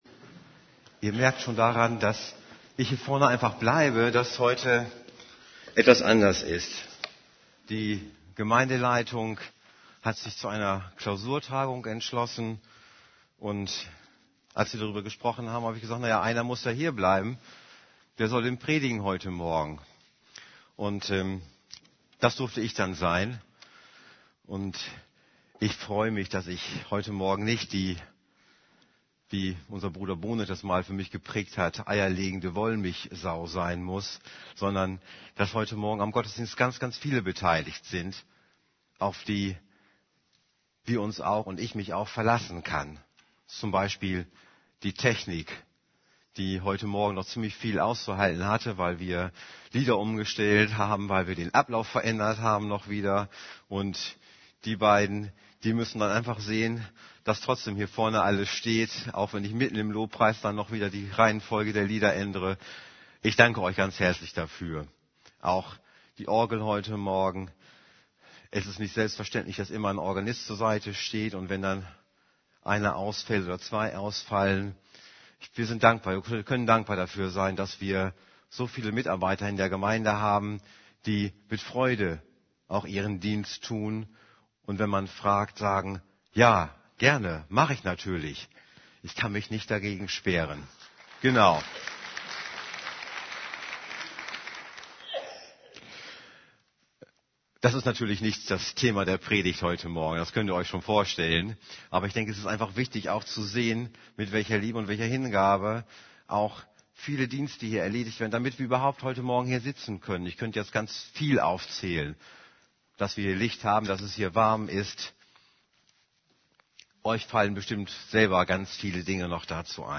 Predigt vom 13. November 2011 Predigt